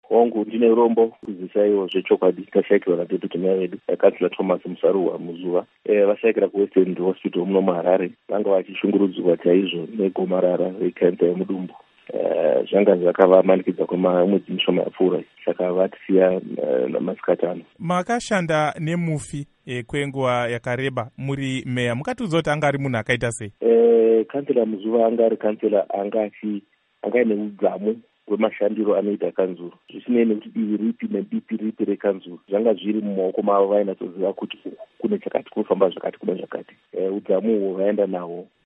Hurukuro naVaBernard Manyenyeni